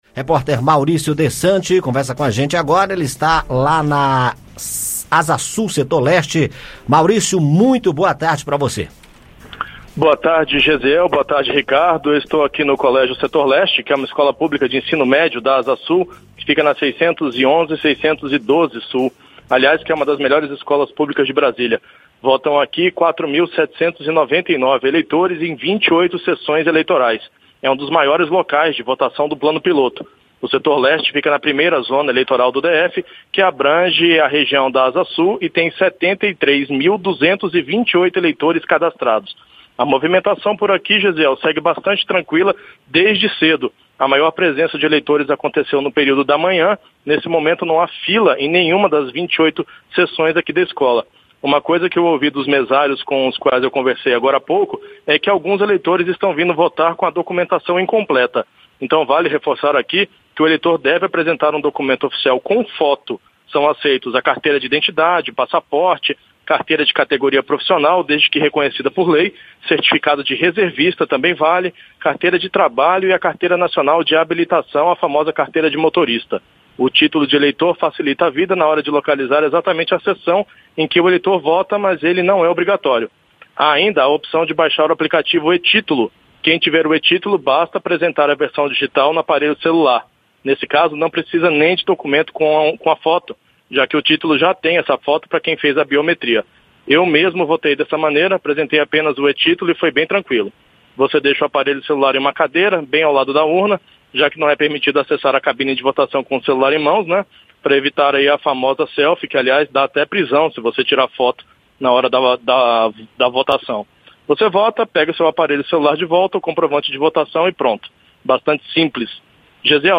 No Colégio Setor Leste, na Asa Sul, mesários alertam para necessidade de eleitor levar documento com foto para votar. São aceitos carteira de identidade, certificado de reservista, passaporte, carteira de categoria de categoria profissional e carteira de habilitação.